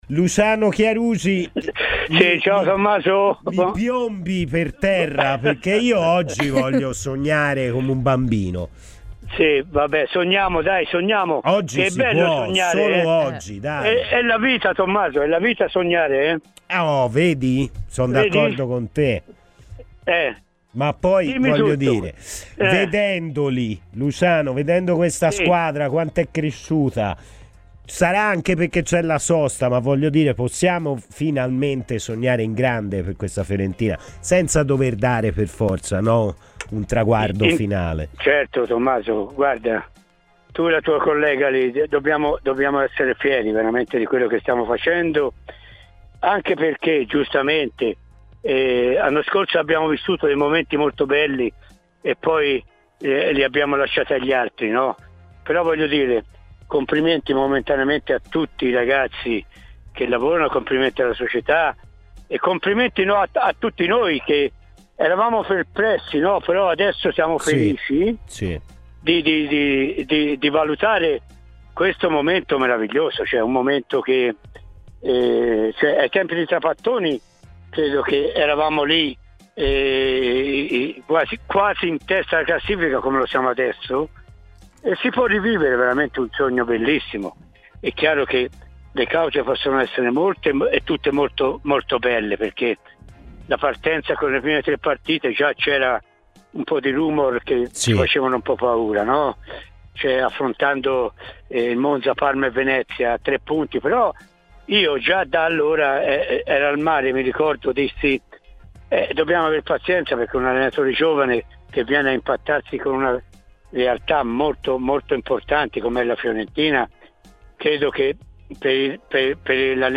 L'ex attaccante della Fiorentina Luciano Chiarugi è intervenuto ai microfoni di Radio FirenzeViola durante la trasmissione "Palla al Centro" per parlare del momento straordinario della formazione gigliata.